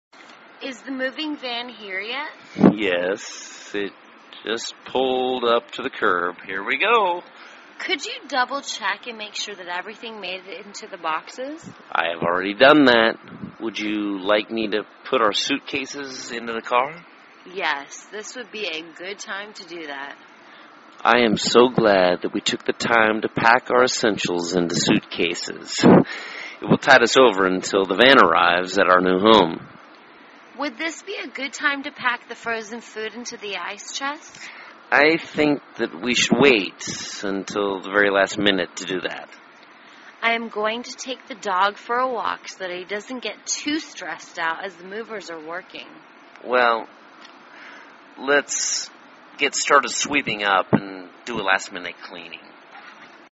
售房英语对话-Moving Day(1) 听力文件下载—在线英语听力室